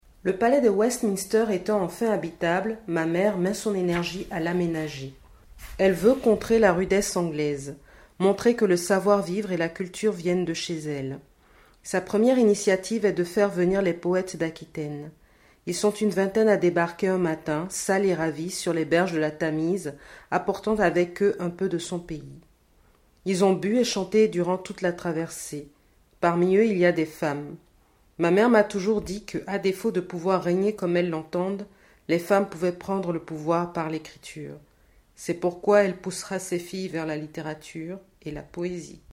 Plutôt que de les reproduire, je vous les lis :